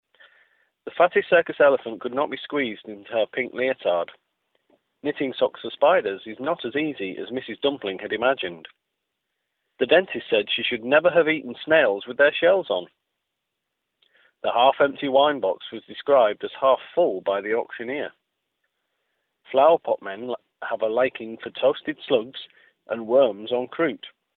standard_male.mp3